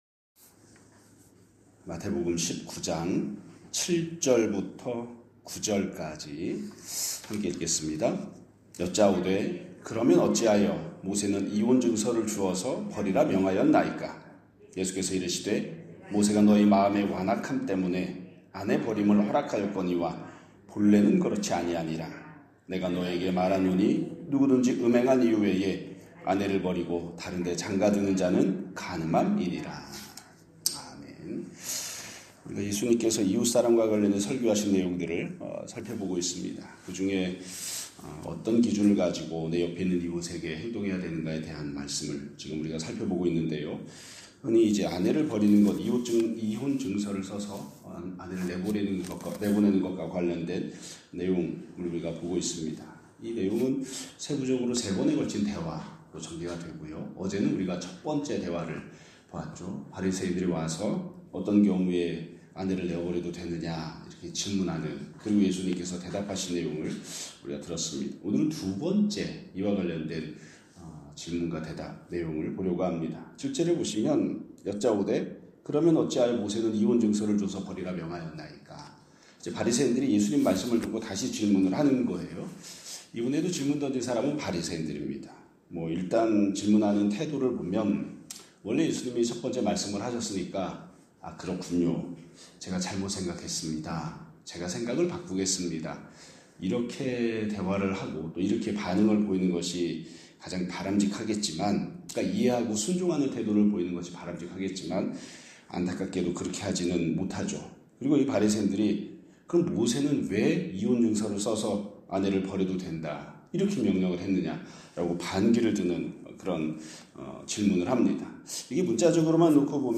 2025년 12월 31일 (수요일) <아침예배> 설교입니다.